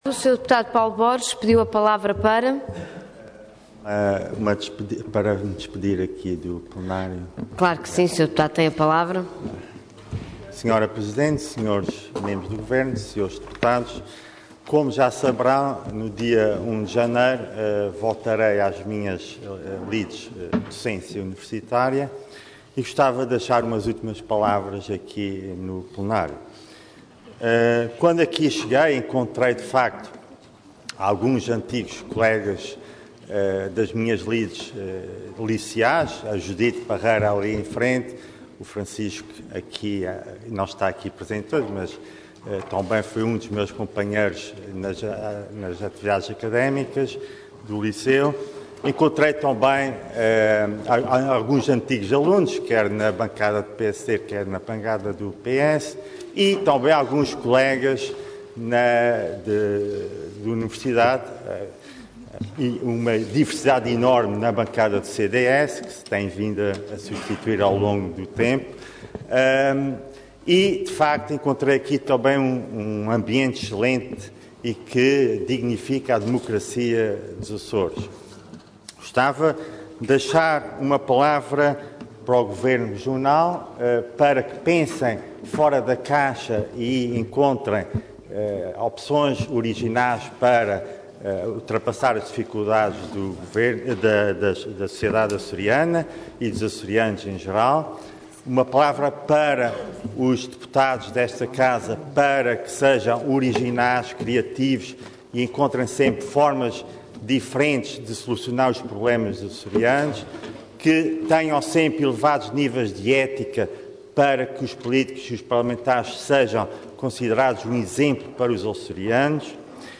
Detalhe de vídeo 12 de dezembro de 2014 Download áudio Download vídeo X Legislatura Declaração de despedida como Deputado Intervenção Orador Paulo Borges Cargo Deputado Entidade PS